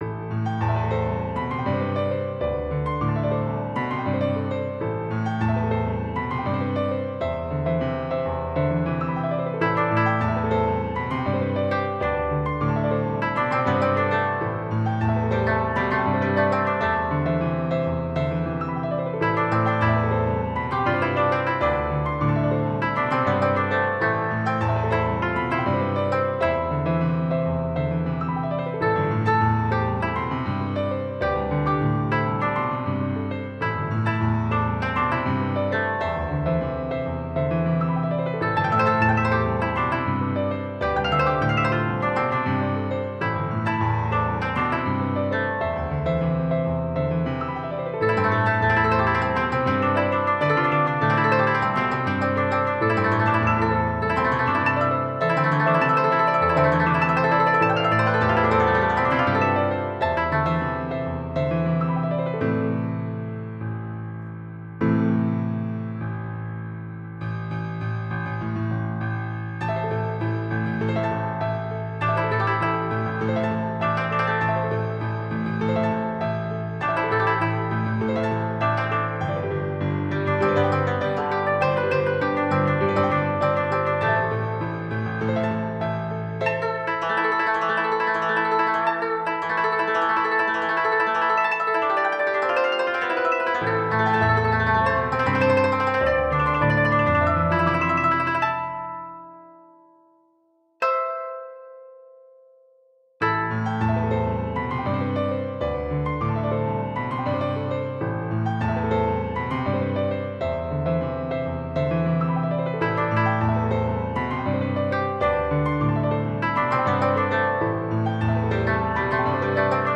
Jazzy blues
Well, Koto and piano.
Jazzy-blues-oriental.mp3